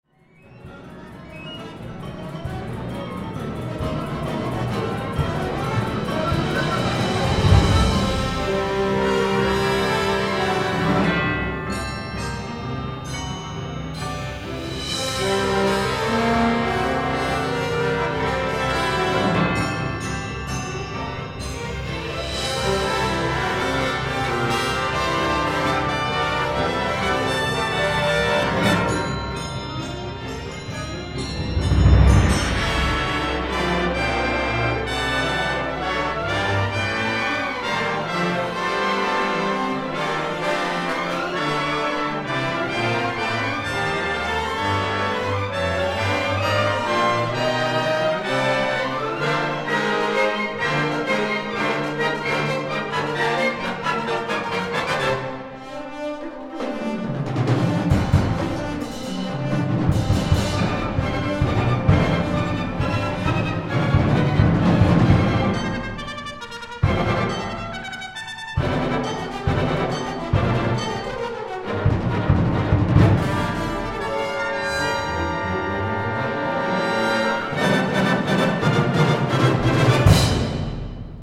Instrumentation: orchestra